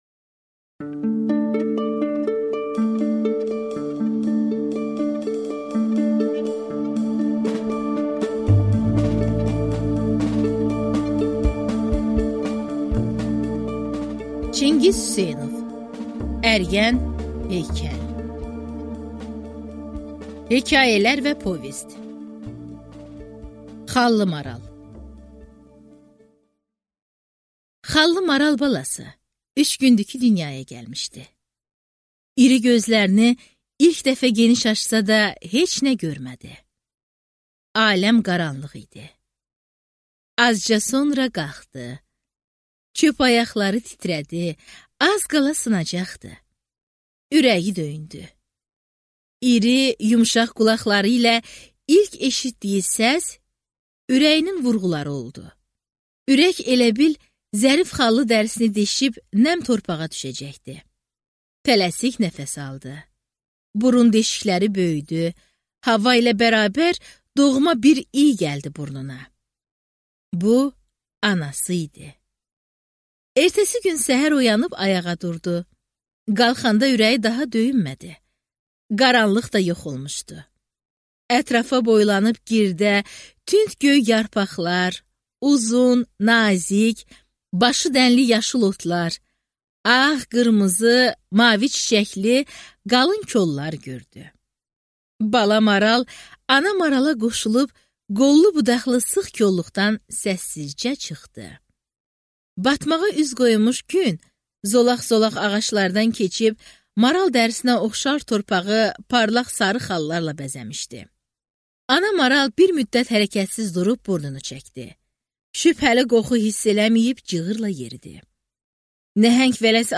Аудиокнига Əriyən heykəl | Библиотека аудиокниг